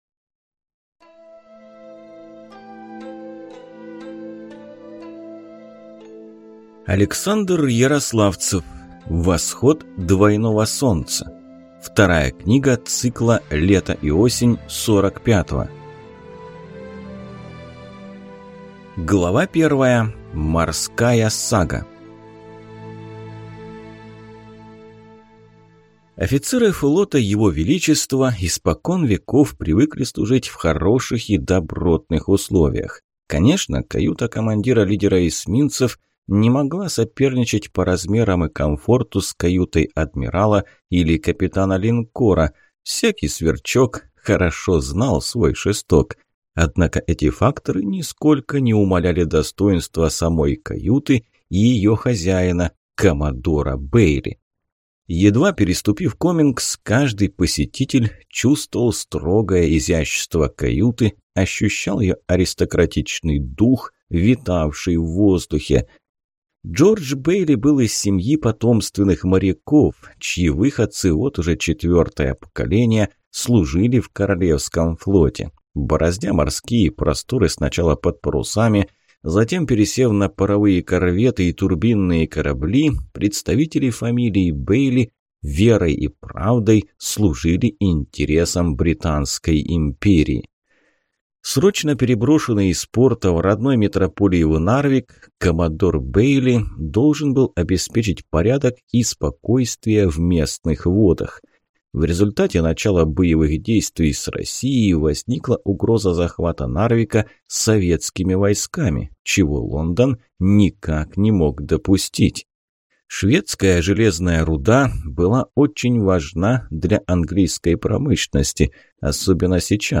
Аудиокнига Восход двойного солнца | Библиотека аудиокниг